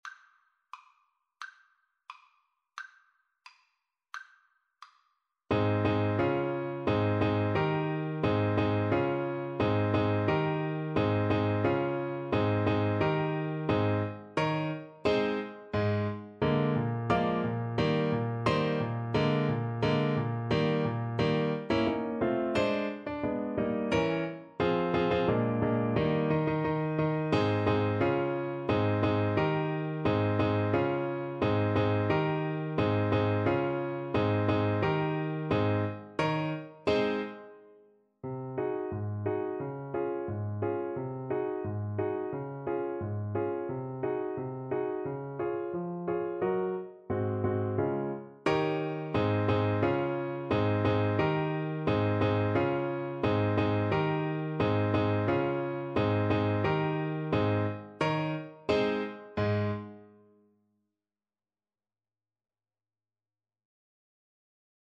Play (or use space bar on your keyboard) Pause Music Playalong - Piano Accompaniment Playalong Band Accompaniment not yet available transpose reset tempo print settings full screen
~ = 88 Stately =c.88
Ab major (Sounding Pitch) F major (Alto Saxophone in Eb) (View more Ab major Music for Saxophone )
Classical (View more Classical Saxophone Music)